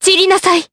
Talisha-Vox_Skill1_Jp.wav